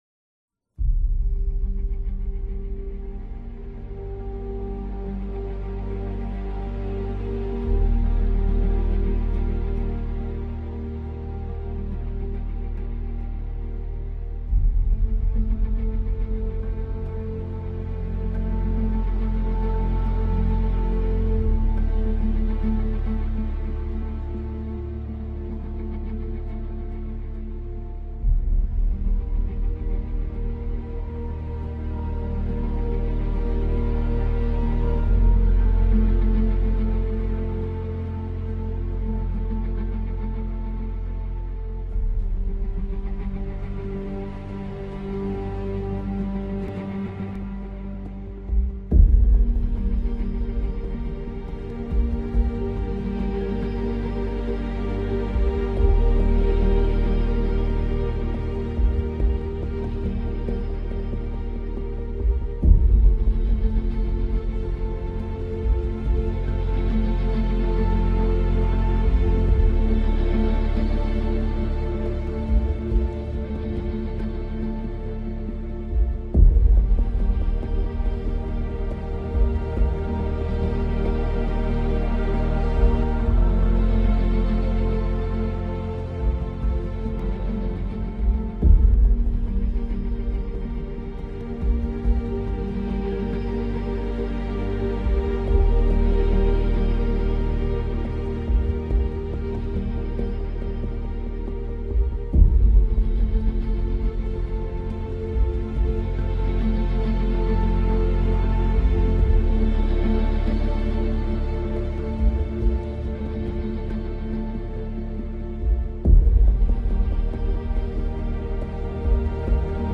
KEBmtMbOLue_Epic-Background-Music-PvKkPlx1Qy4.mp3